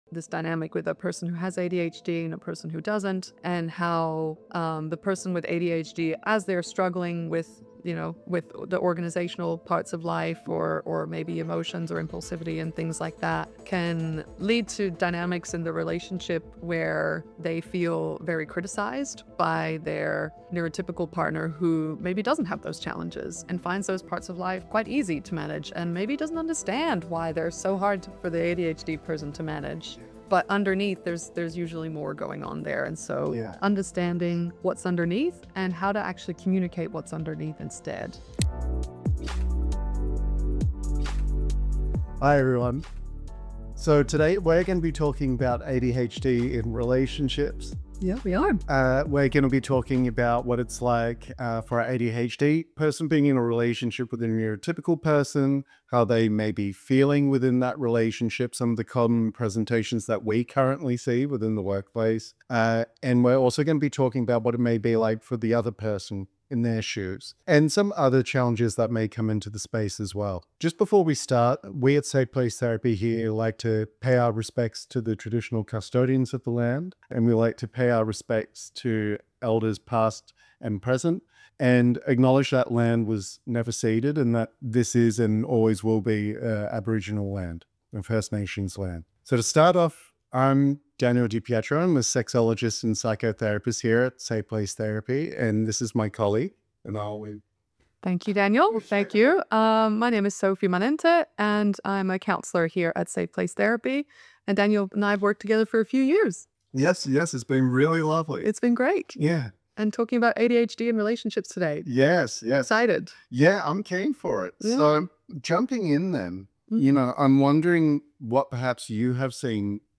Therapists discuss ADHD in relationships and what it's like for an ADHD person being in a relationship with a neurotypical person. How they may be feeling within that relationship, some of the common presentations.